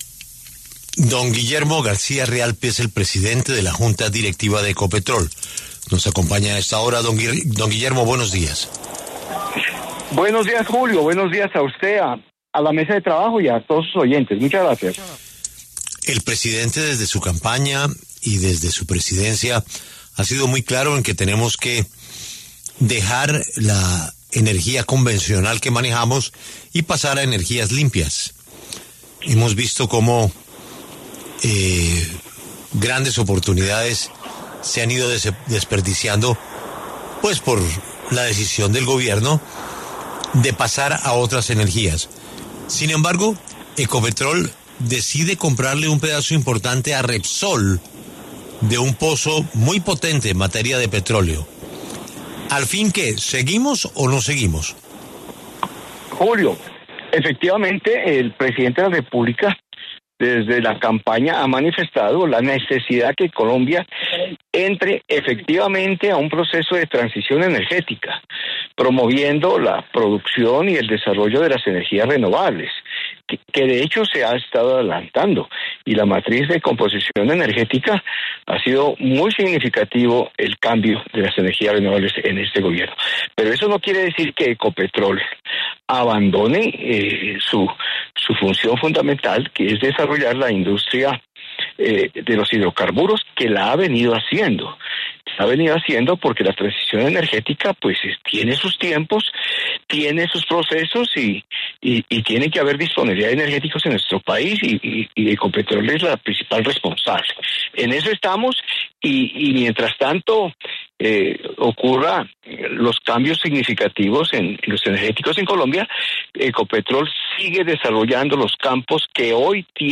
Guillermo García Realpe, presidente de la junta directiva de Ecopetrol, habló en La W de la adquisición del 45% de Repsol en un bloque clave del Meta. Así, suma 41 millones de barriles a sus reservas en medio de críticas por su rol en la transición energética.